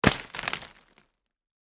UI_GravelRoll_02.ogg